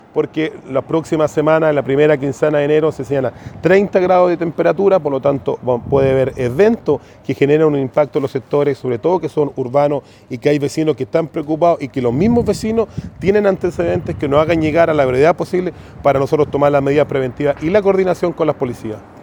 El alcalde de Penco, Rodrigo Vera, condenó estos hechos.